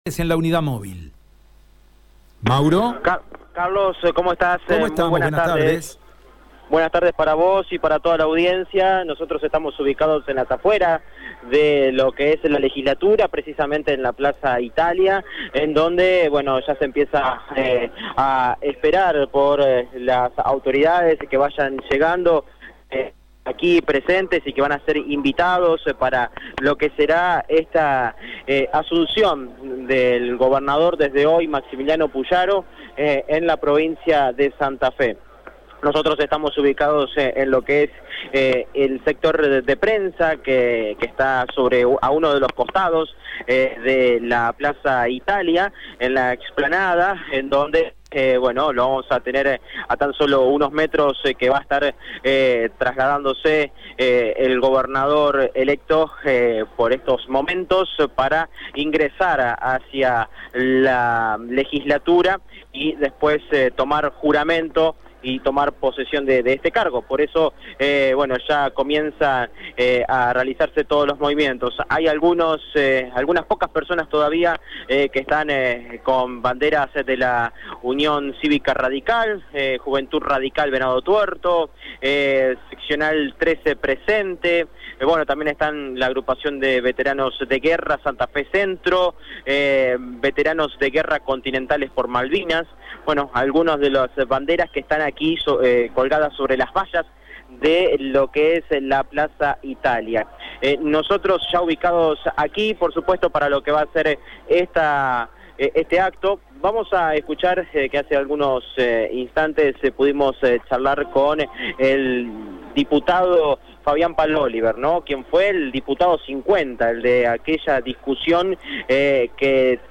En el ingreso a la Legislatura santafesina, Radio EME está presente con su móvil para tomar contacto con los principales políticos en la asunción de Maximiliano Pullaro como Gobernador de la Provincia de Santa Fe.